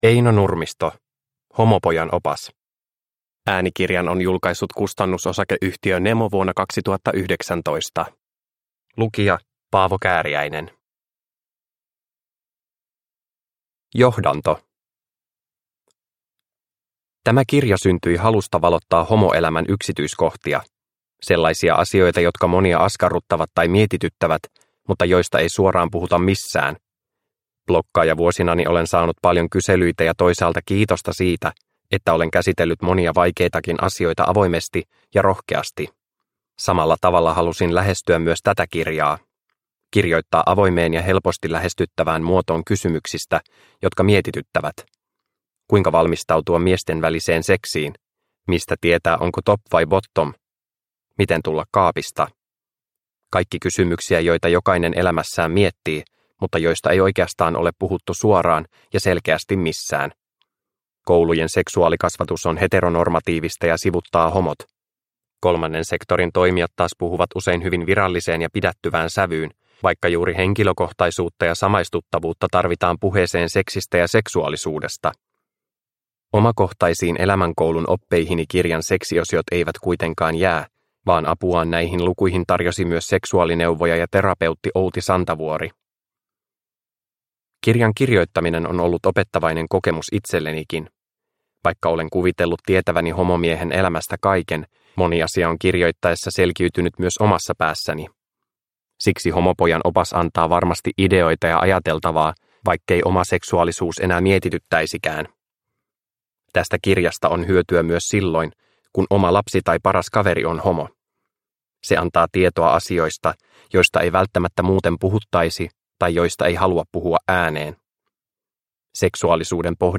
Homopojan opas – Ljudbok – Laddas ner